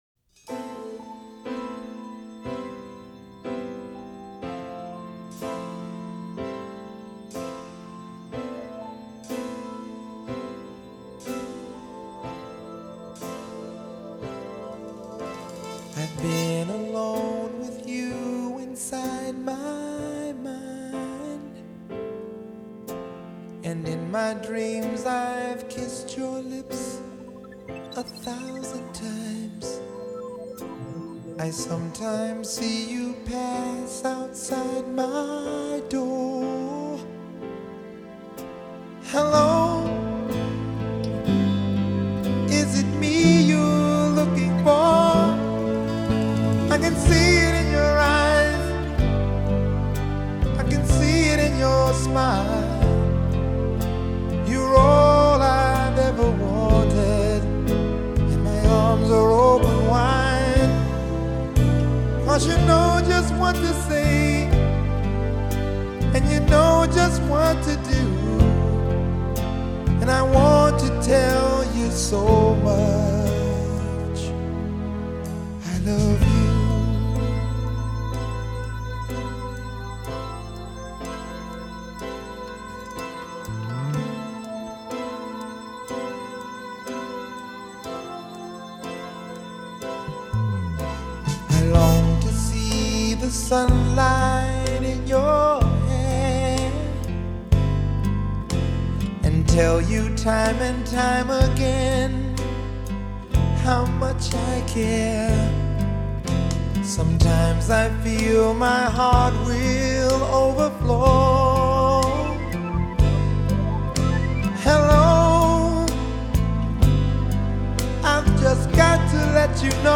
Genre : R&B.